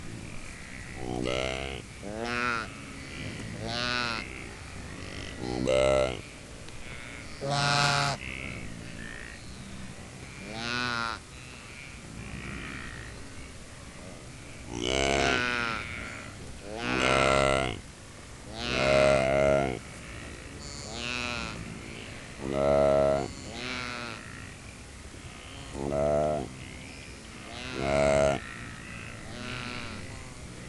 Звуки сайги несколько сайг разговаривают между собой в стае